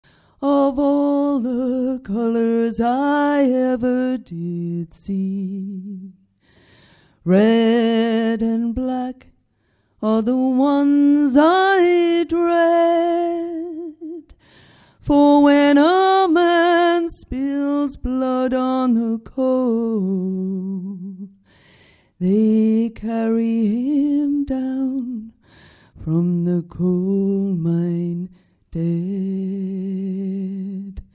Ashington Folk Club - Spotlight 21 July 2005
in fine voice. She sang an American traditional song called  'Red Winged Blackbird' and then another traditional song (with a warning to women about men!)